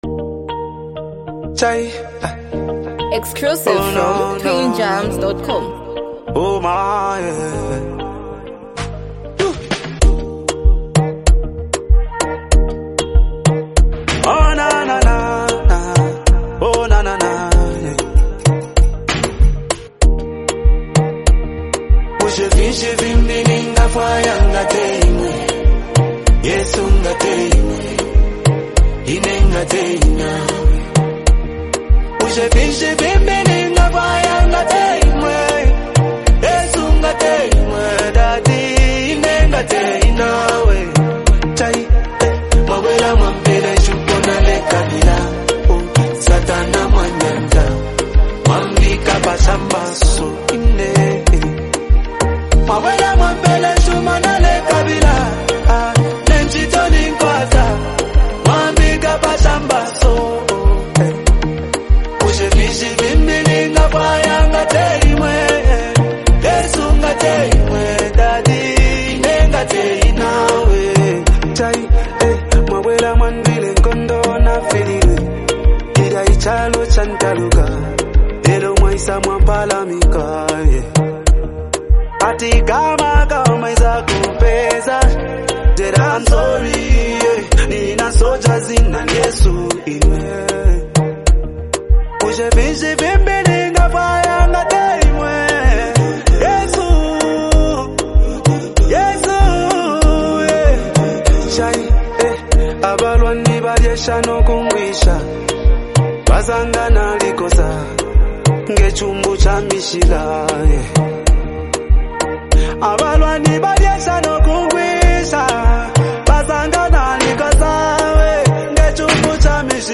a deeply spiritual and emotional song